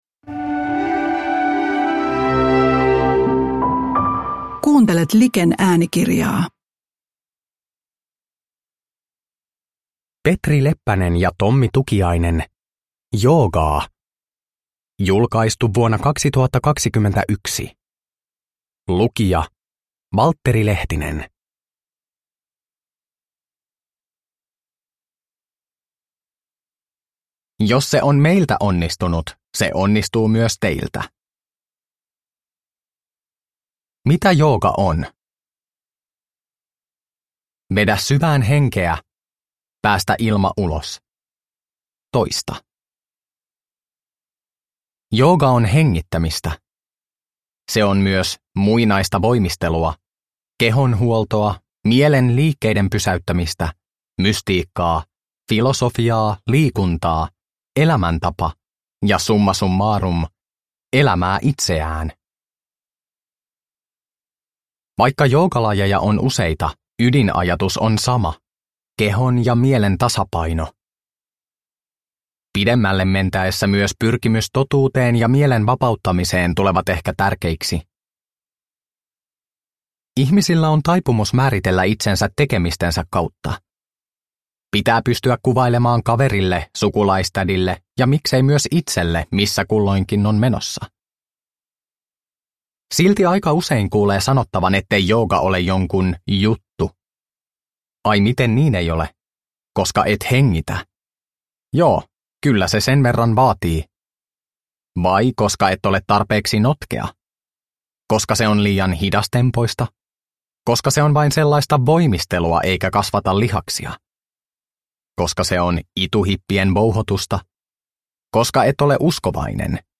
Joogaa! – Ljudbok – Laddas ner